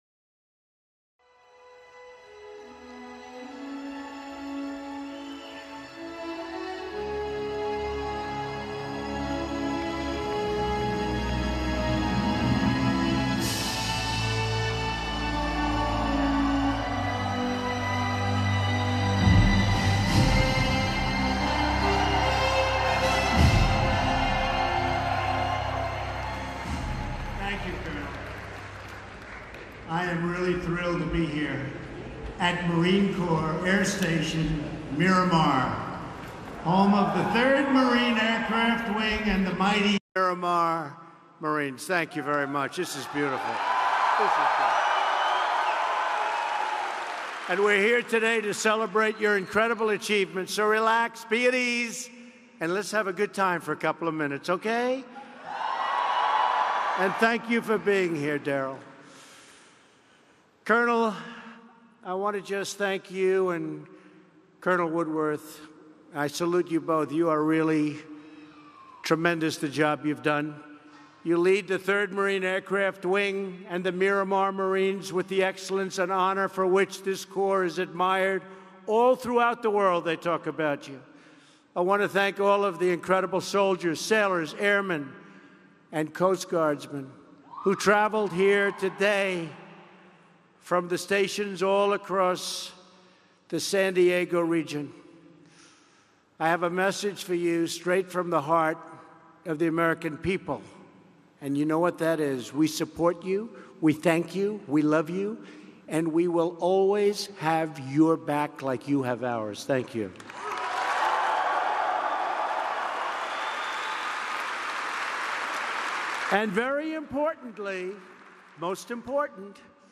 U.S. President Donald Trump addresses troops at Miramar
President Donald Trump addresses troops at Miramar, the United States Marine base in San Diego, just hours after reviewing border wall prototypes at a nearby port of entry. Trump recounts the history of the Marine Corp, then talks about his visit to see the prototypes, immigration enforcement, and investment in the U.S.